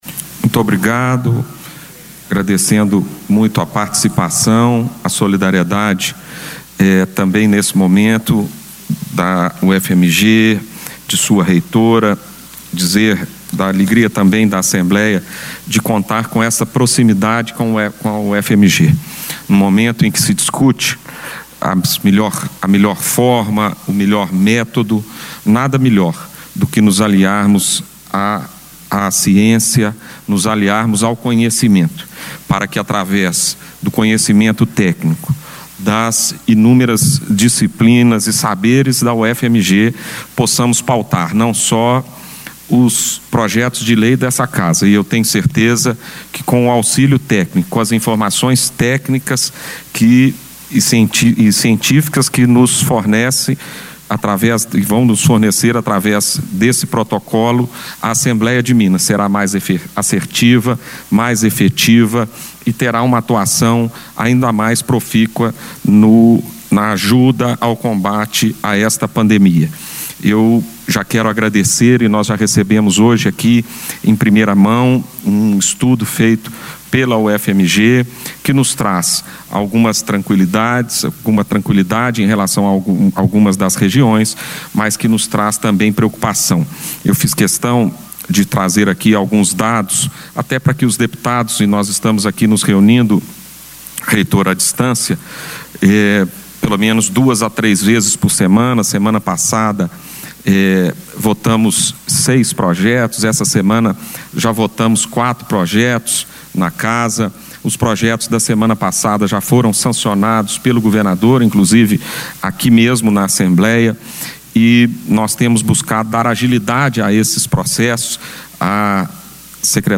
O pronunciamento durante solenidade protocolo de intenções para a cooperação técnica entre a ALMG e a UFMG, traz informações sobre ações do Legislativo para conter a pandemia e dados atualizados a respeito da demanda por atendimento hospitalar.